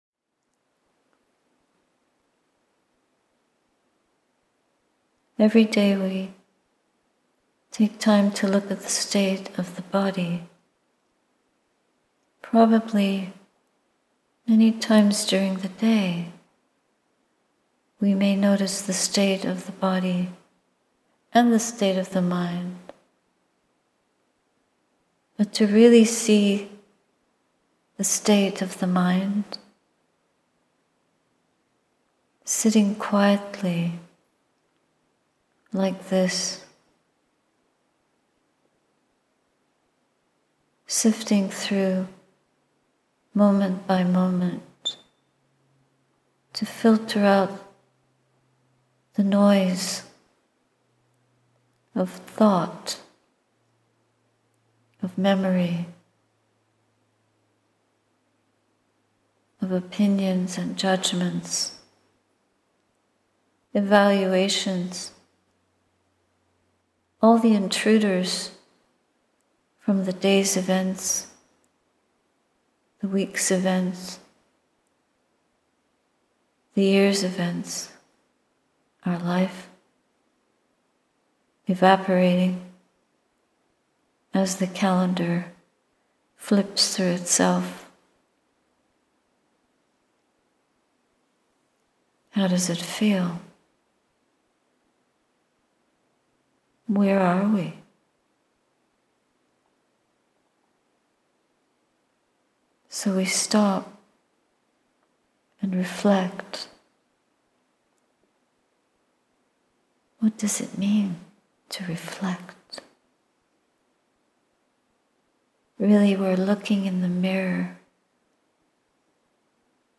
Silent Homage – Guided Meditation
Ottawa Buddhist Society meditation, June 2025